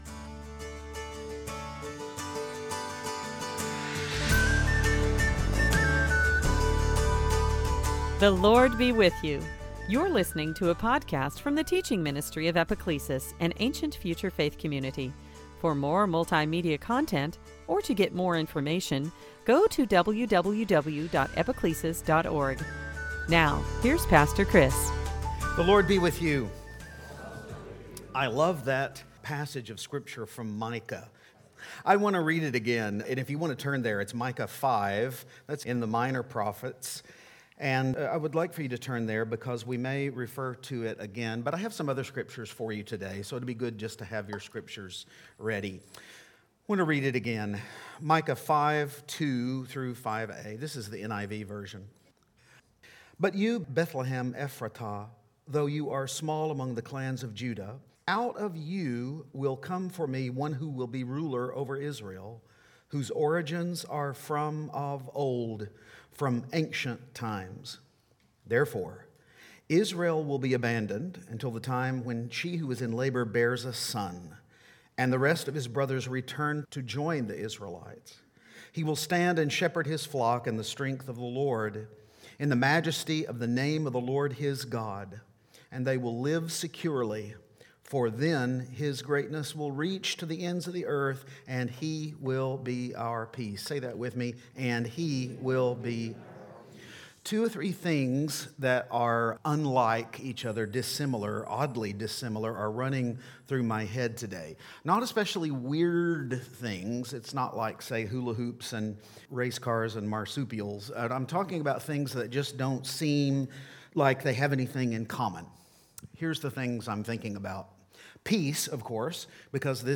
Series: Sunday Teaching
Service Type: Advent